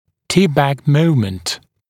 [‘tɪpbæk ‘məumənt][‘типбэк ‘моумэнт]момент, приводящий к дистальному наклону зуба, опрокидывающий момент